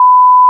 Звуки-приколы